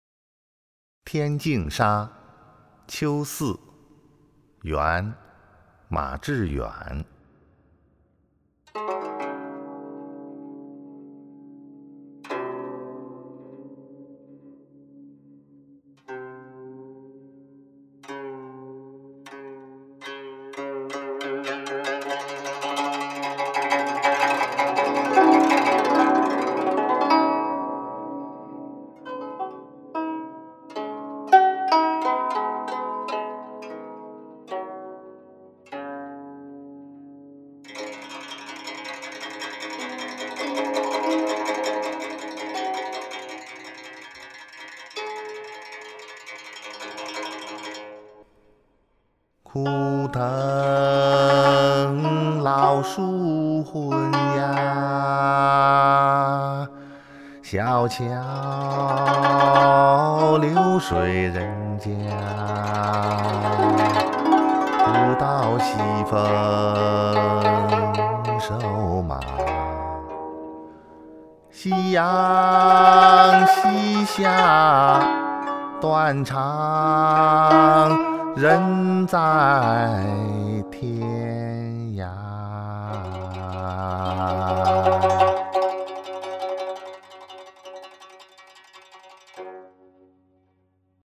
［元］马致远《天净沙·秋思》（吟咏）